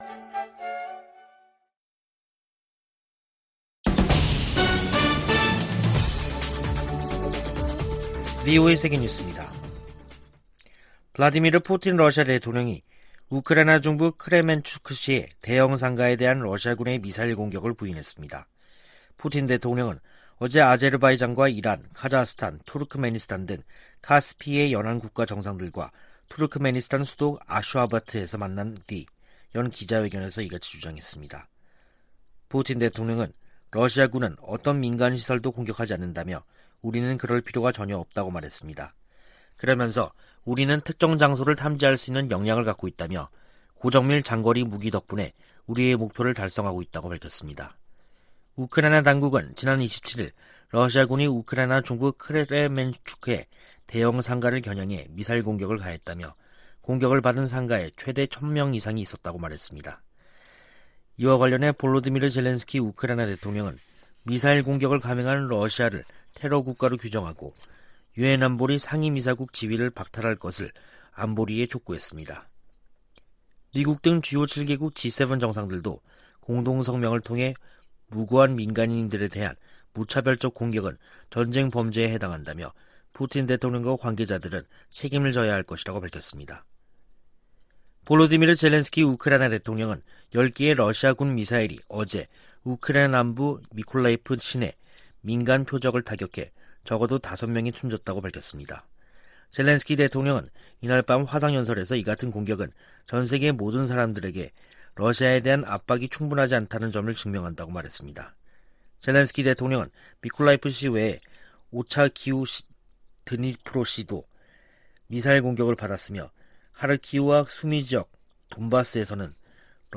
VOA 한국어 간판 뉴스 프로그램 '뉴스 투데이', 2022년 6월 30일 2부 방송입니다. 백악관은 조 바이든 대통령이 한국·일본 정상과 협력 심화를 논의한 역사적인 회담을 열었다고 발표했습니다. 미 공화당 상원의원들은 나토가 중국과 러시아의 위협에 동시에 대응하도록 할 것을 바이든 대통령에게 촉구했습니다. 냉각된 한일관계에 개선은 대북 억제와 중국 견제를 위해 중요하다고 미국 전문가들이 진단했습니다.